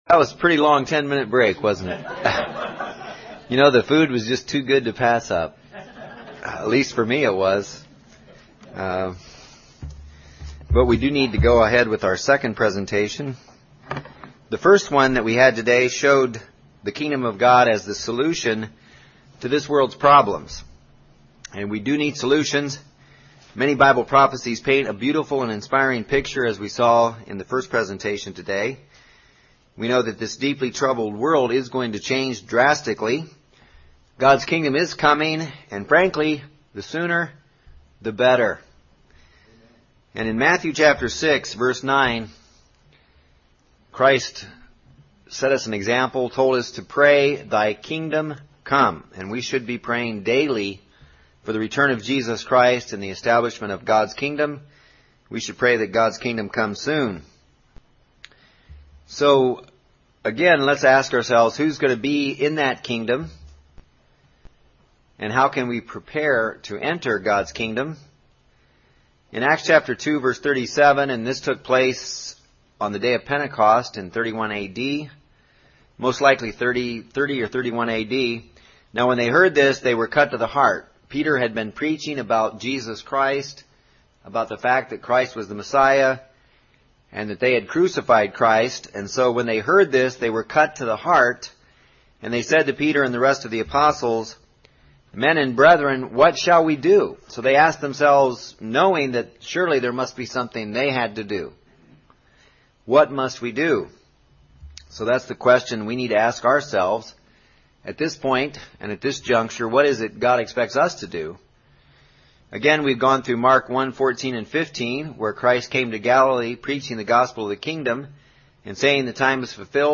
Kingdom of God Bible Seminar, Session Two Repentance is key to becoming a member of God's holy kingdom.